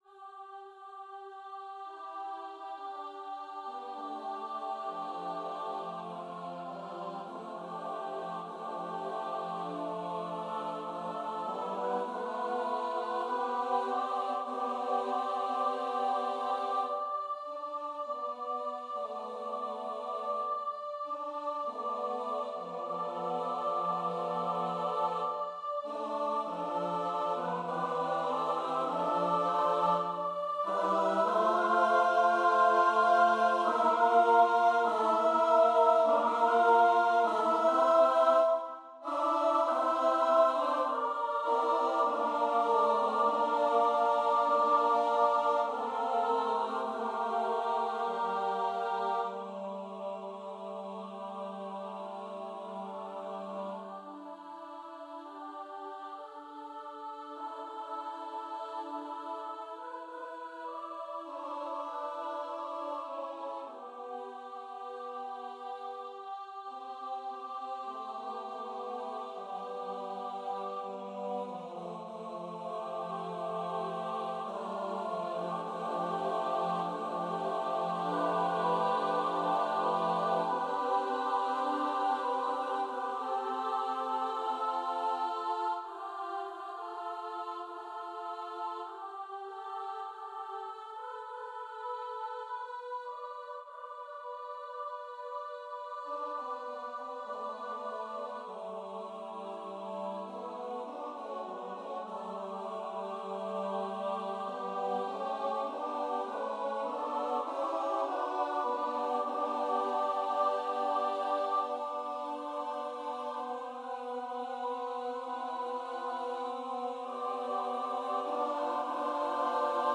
Do not go gentle into that good night (for SATB unaccompanied, with divisi)